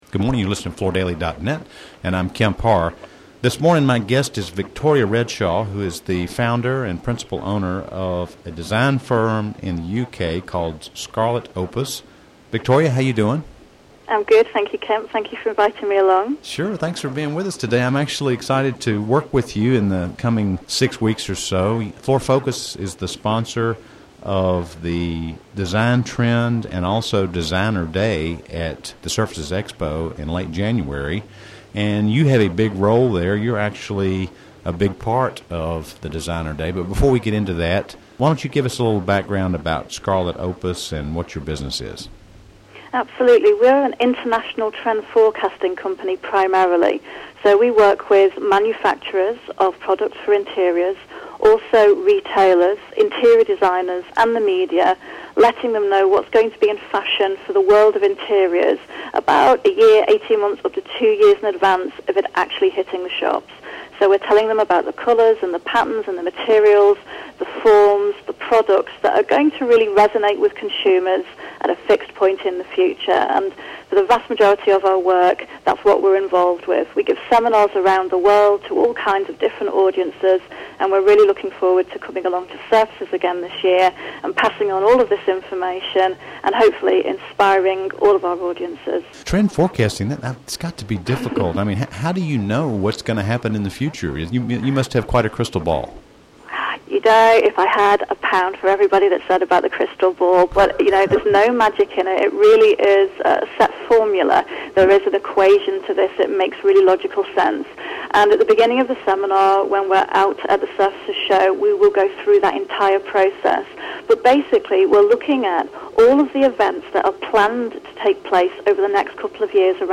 Hanley Wood is putting a major emphasis toward augmenting the Surfaces Show experience next year in Vegas and this component is sure to be popular. Listen to the interview to hear more details about this exciting new Surfaces dimension--which Floor Focus has agreed to put our name behind as the sole sponsor.